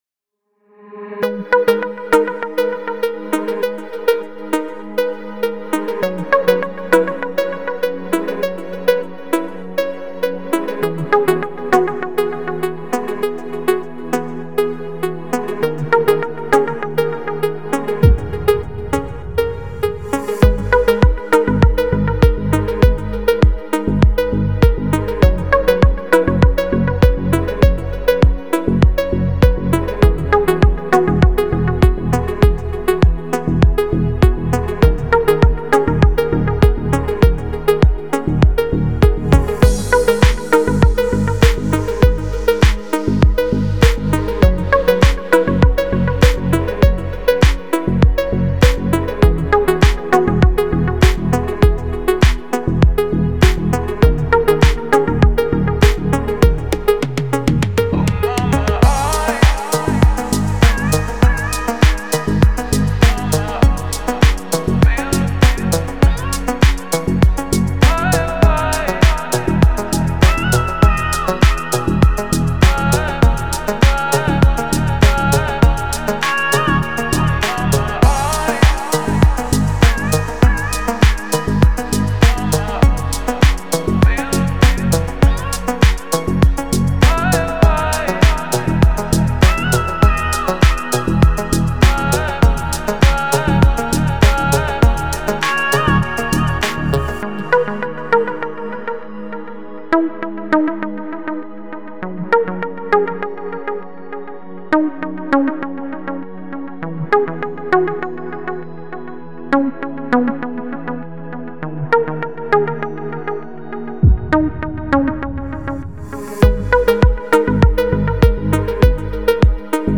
دیپ هاوس , ریتمیک آرام , موسیقی بی کلام